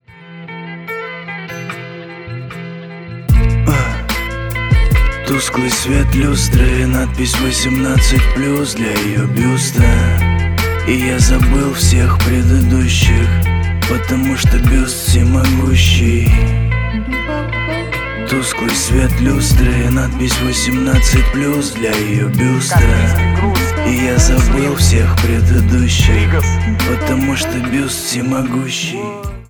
интро
рэп